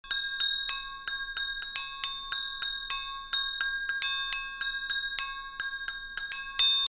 LP Agogo Bells - Standard (LP231A)
They are LP's interpretation of the instrument that comes from Brazil and are distinctly brighter in sound than the original versions.
They are made of 18 gauge steel and are relatively pitched a minor third apart.
These are super ringy and high pitched and really don't sound like typical agogo bells at all.